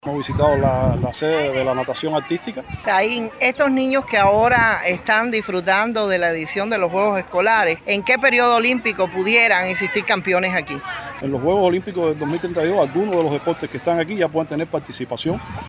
Ariel Saínz Rodríguez, vicepresidente del INDER
Saínz Rodríguez aseguró que: